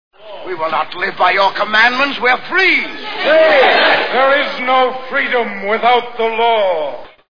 Ten Commandments Movie Sound Bites